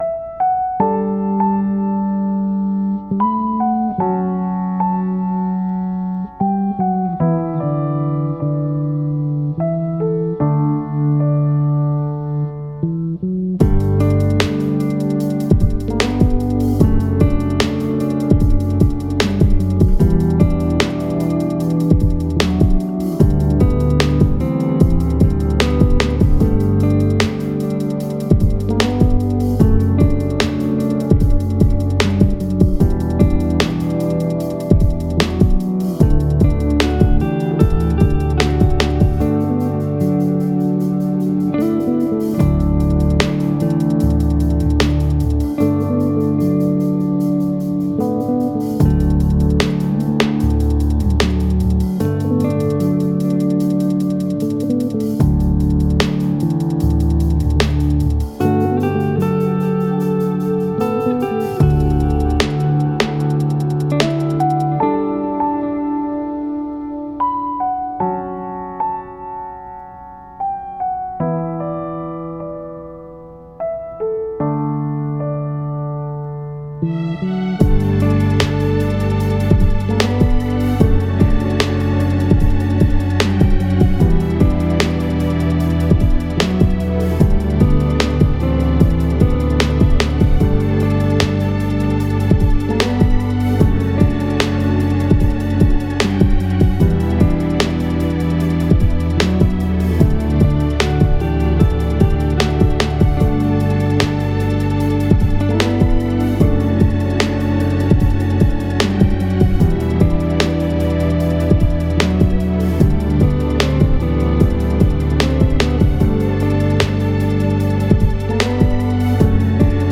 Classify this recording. Genre: chillhop.